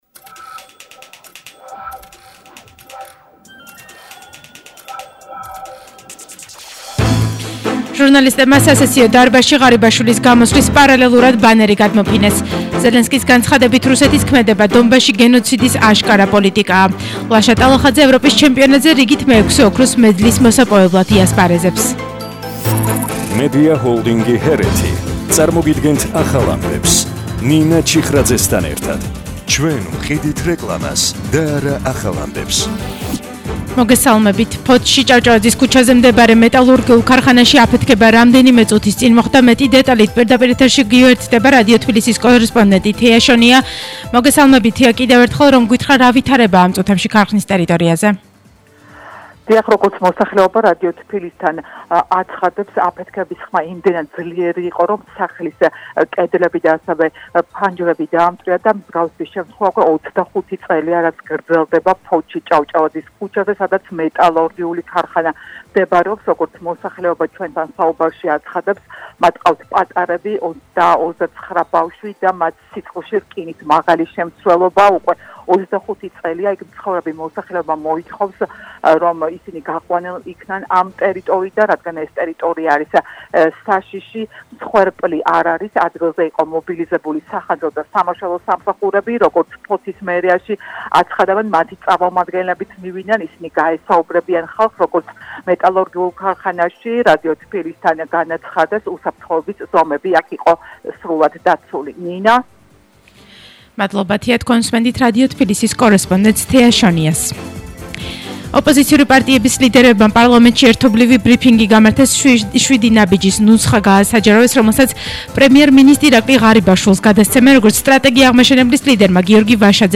ახალი ამბები 16:00 საათზე – 27/05/22